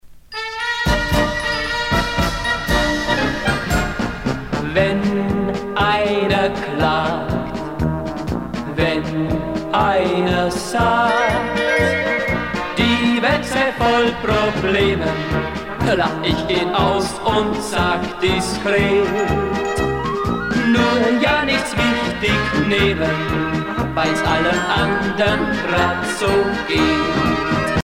danse : marche-polka
Pièce musicale éditée